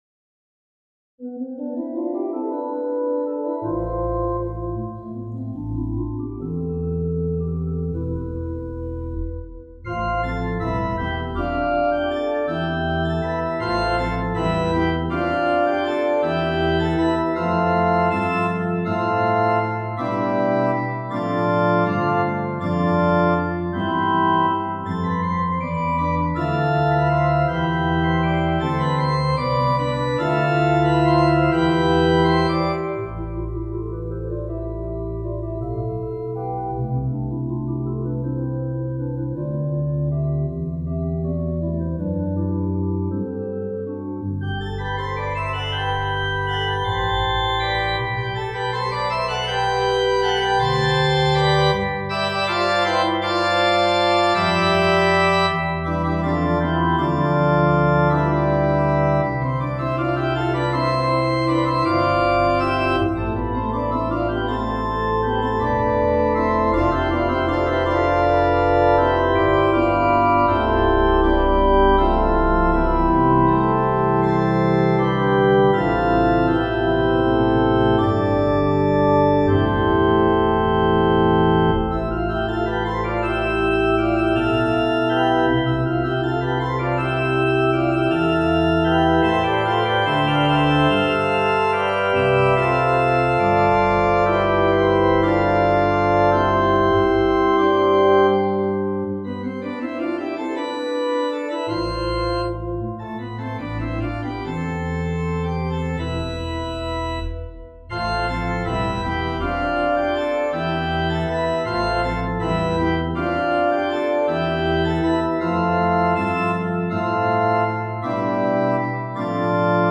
for organ
Here 10/8 is framed as 2+2+3+3. Light changes of registration for variety are recommended.